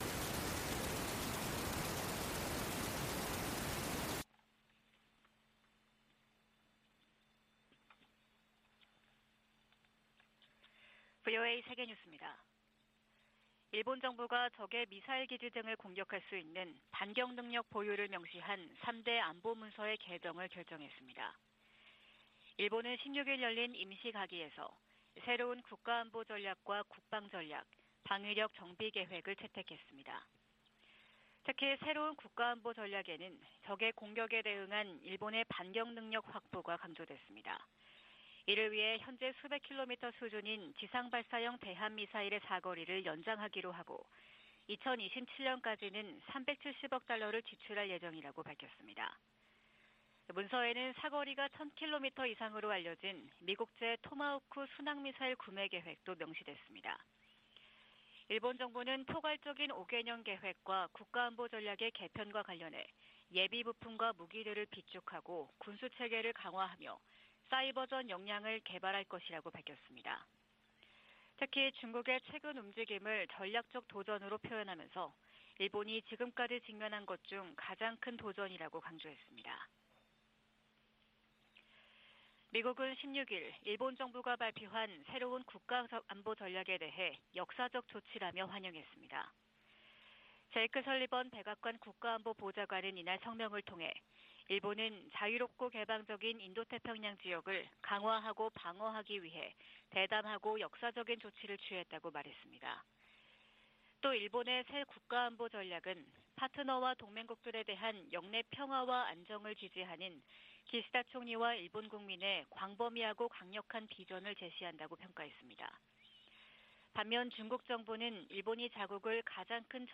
VOA 한국어 '출발 뉴스 쇼', 2022년 12월 16일 방송입니다. 북한이 대륙간탄도미사일로 보이는 고출력 고체엔진 시험에 성공했다고 주장했습니다.